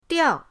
diao4.mp3